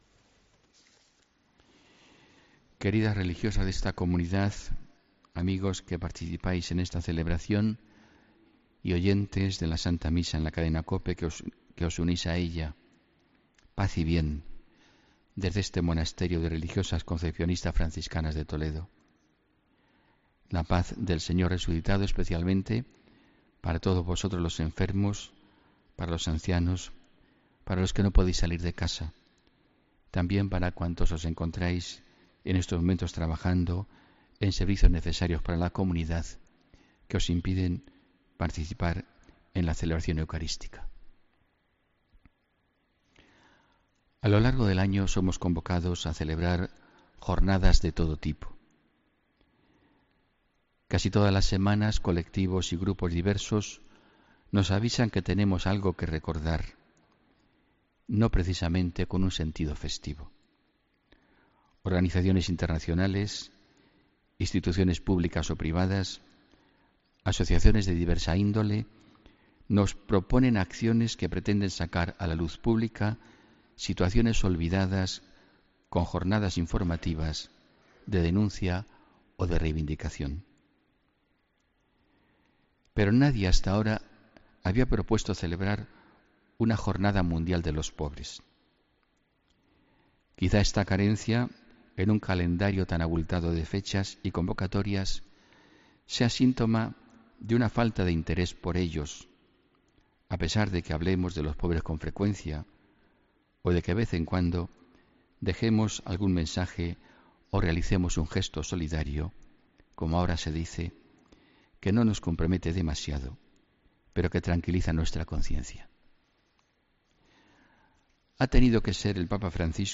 HOMILÍA 19 DE NOVIEMBRE